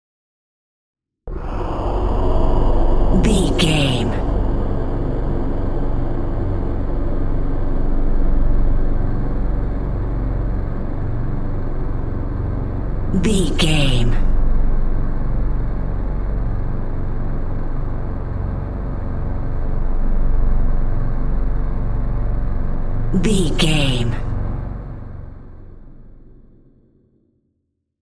Suspense Drone Pad Trailer
Sound Effects
Atonal
magical
mystical